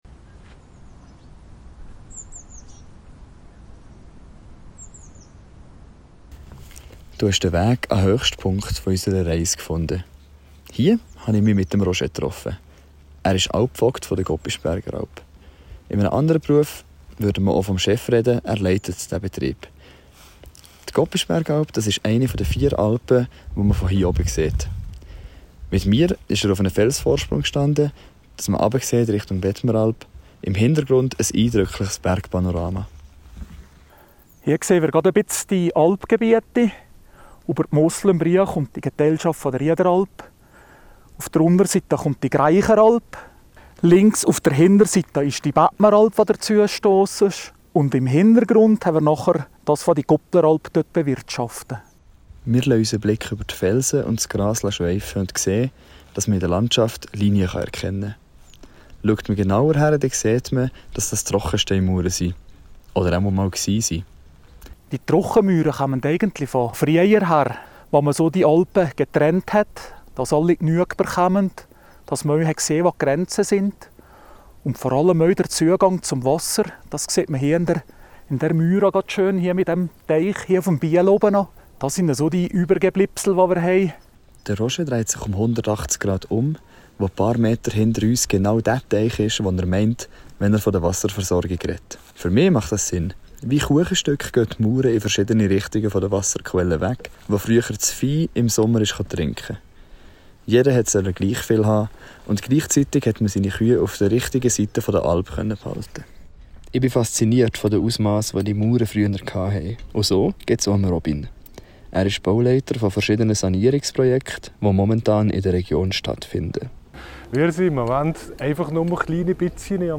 Stimmen aus der Praxis